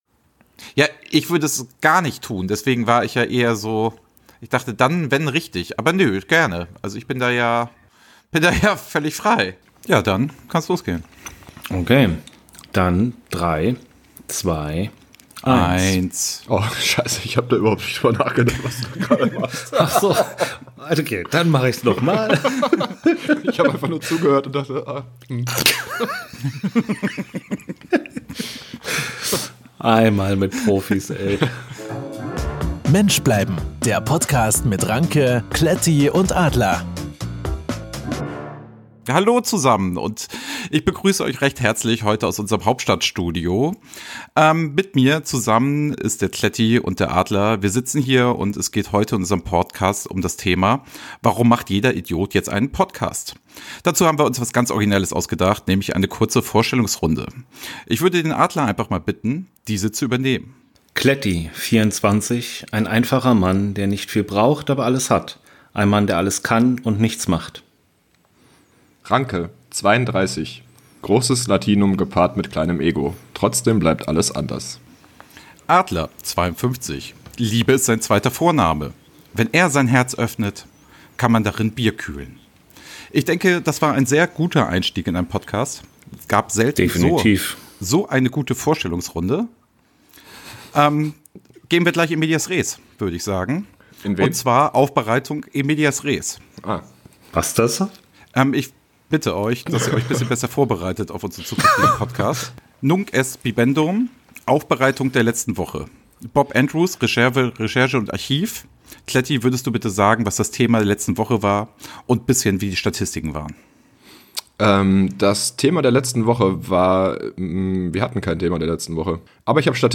In dieser Folge gehen die drei Podcastern der Frage nach: Warum macht jeder Idiot jetzt einen Podcast. Gewohnt charmant, witzig und inspirierend nähern sie sich mit messerscharfen Analysen und einem Blick in die Historie dem Thema. Für Fans der ersten Stunde geht es natürlich auch um Salzstreuer.